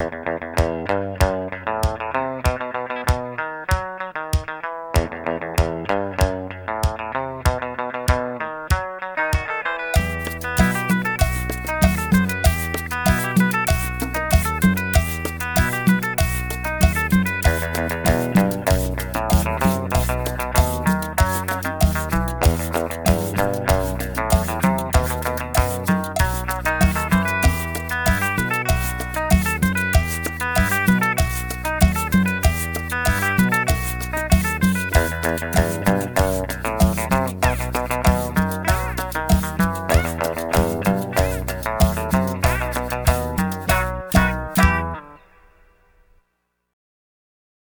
кумбия (закрыта)